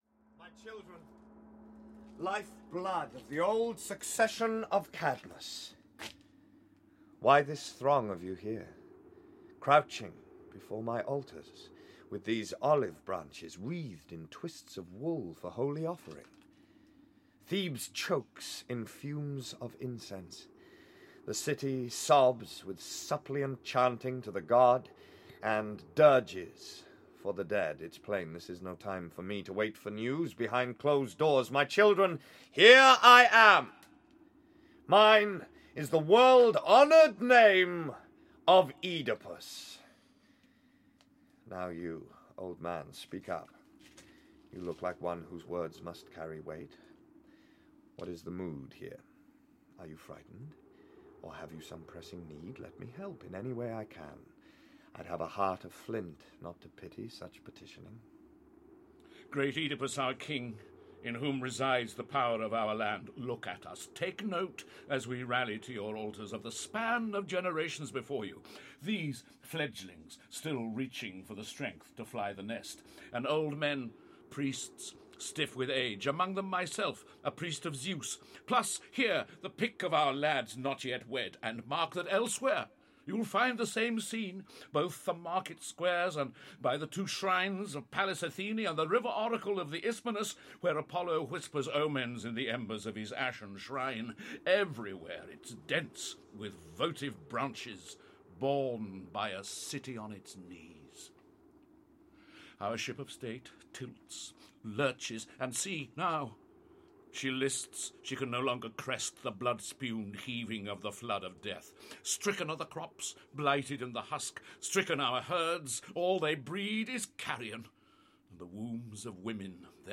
Ukázka z knihy
This new translation of the great classic of Ancient Greece starts a cycle of drama recordings by Naxos AudioBooks. The anguished tale of Oedipus, who having solved the riddle of the Sphinx and become King of Thebes, gradually realises the crimes he has, unwittingly, committed, remains a drama of unremitting power 2,500 years after it was written. With full drama values, Naxos AudioBooks the atmosphere of the Greek amphitheatre to the soundworld of CD, with the outstanding young actor Michael Sheen – recently seen as Henry V (RSC) and Amadeus (West End) and on film and TV.